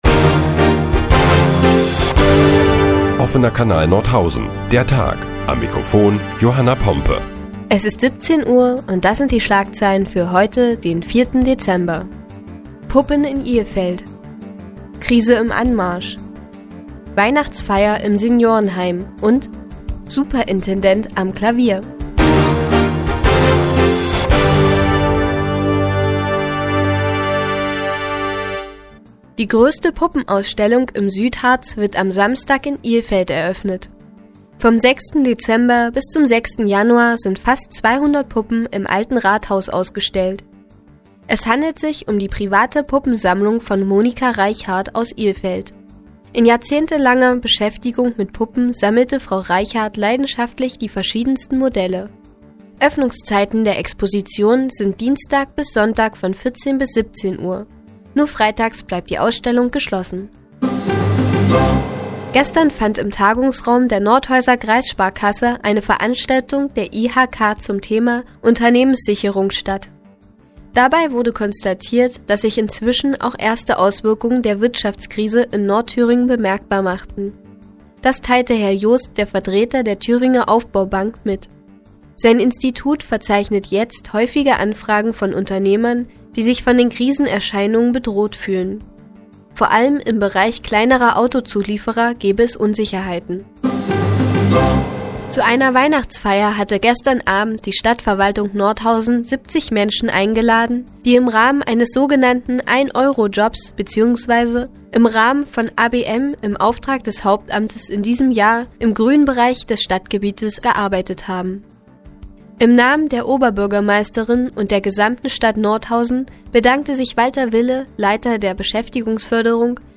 Die tägliche Nachrichtensendung des OKN ist nun auch in der nnz zu hören. Heute geht es unter anderem um eine Puppenausstellung in Ilfeld und die ersten Auswirkungen der Wirtschaftskrise in Nordthüringen.